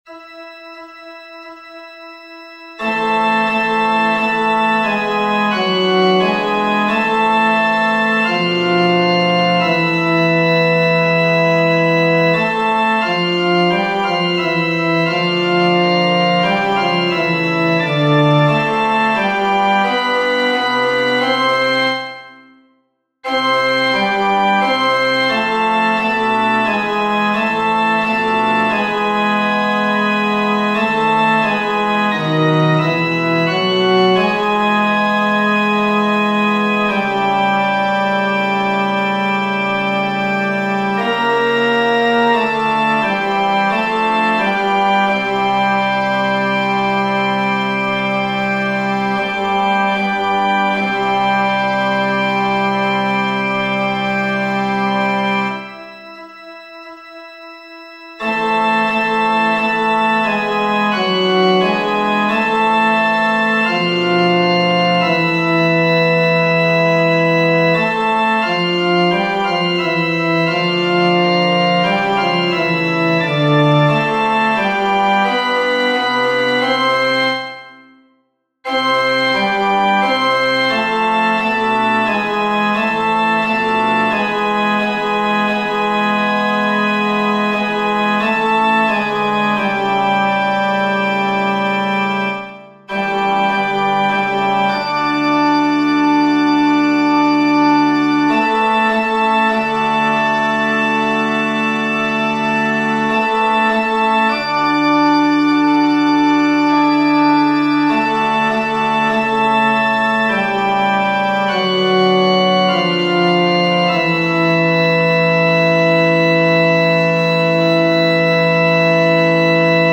Modlitba-baryton.mp3